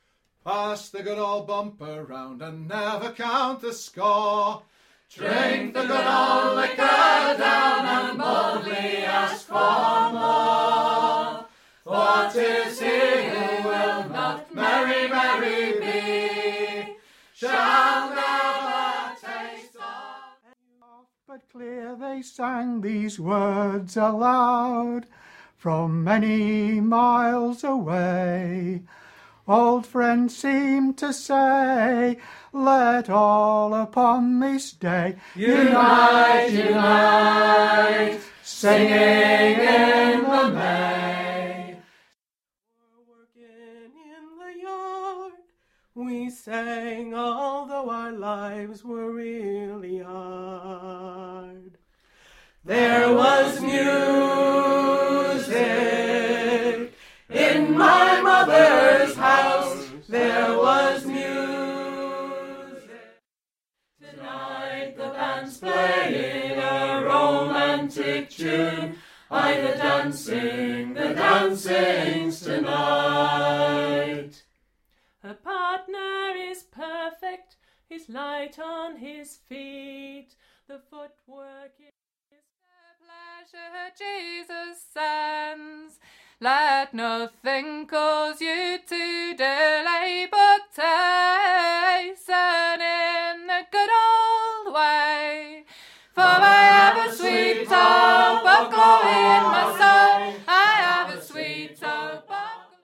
a capella ) singing sessions here and here and here .
It's possibly my most favourite singing session ever, because of all the glorious harmonies.
unaccompanied song sessions. Every month, I leave the pub with my ears ringing, wishing that I could take away some of the joyful choruses to listen to on my way home and to share with other people.
With your help, we are hoping to release these in CD form, to listen to, to make available to the public, and to preserve something of the unique folk tradition that has evolved here.